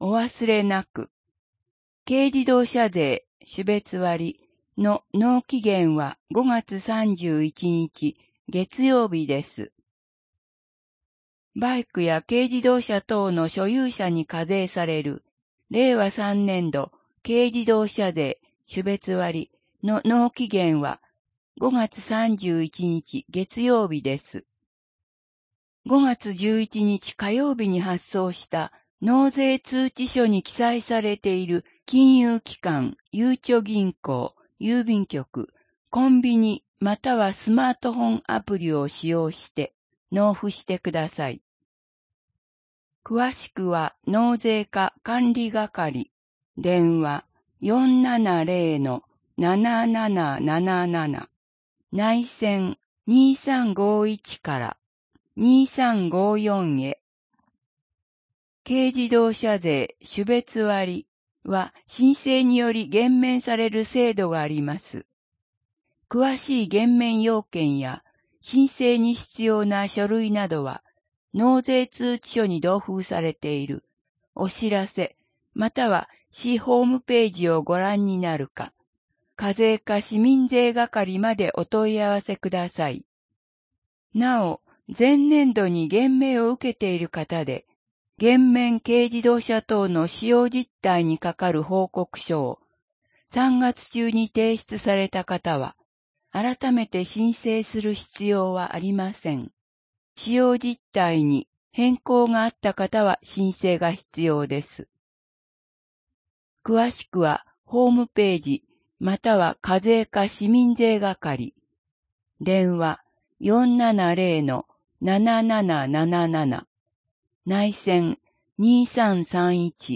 声の広報（令和3年5月15日号）